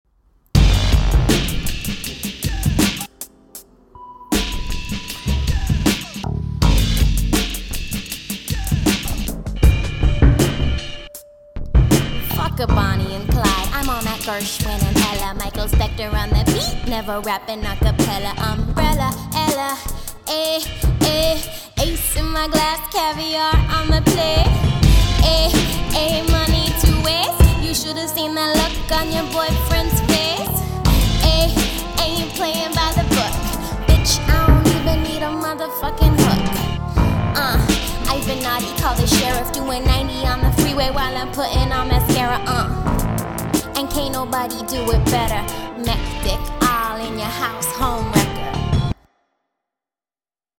Hip Hop
intricate, inspired beats